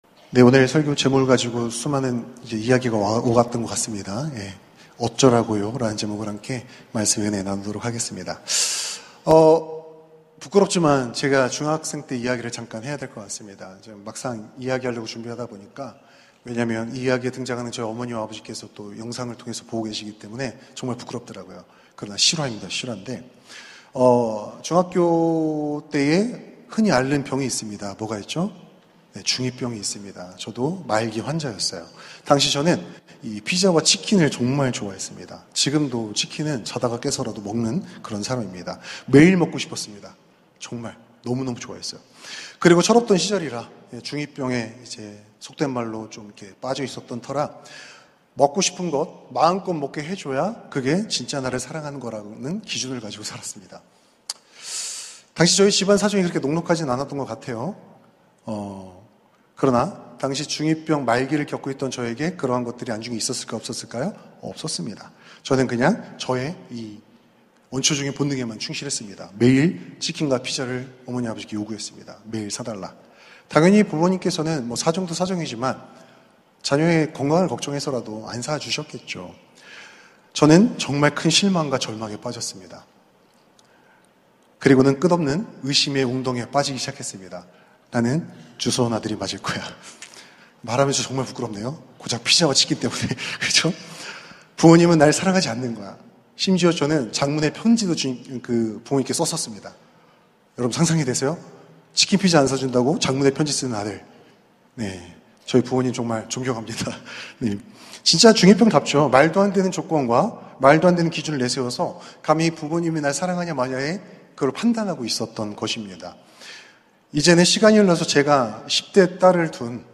설교 : 파워웬즈데이 어쩌라고요